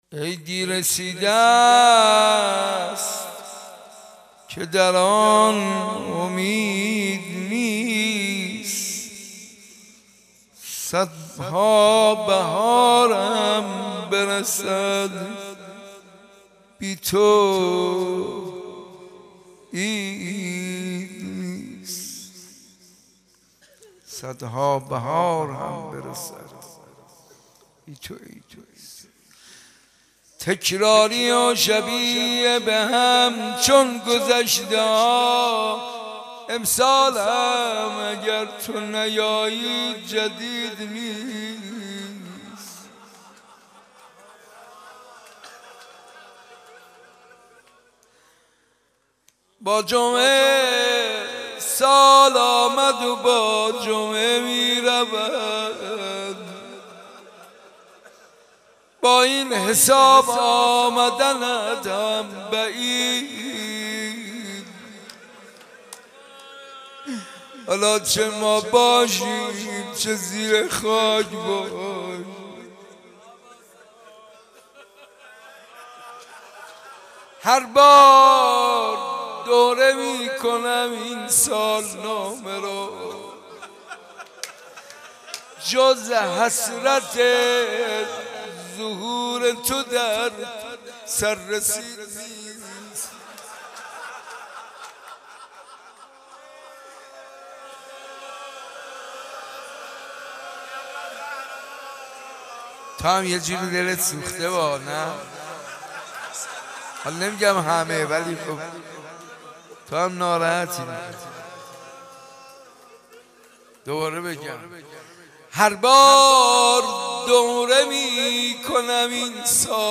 دومین روز از مراسم عزای فاطمی و زیارت عاشورا در حسینیه صنف لباس فروشان